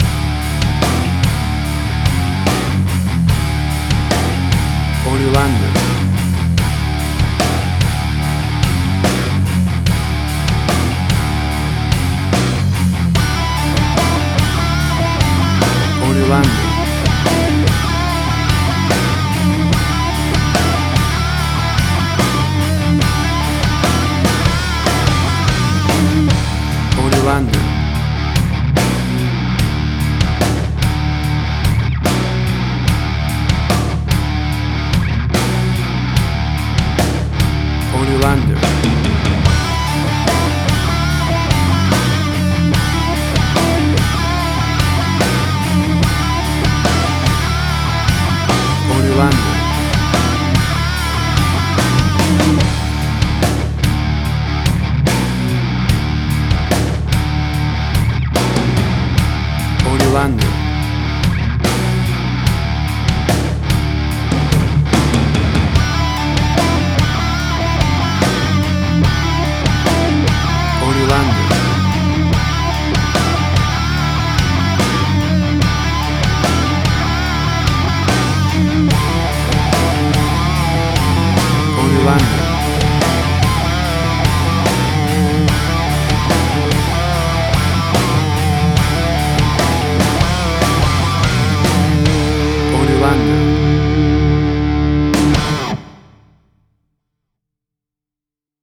Hard Rock
Heavy Metal
Tempo (BPM): 73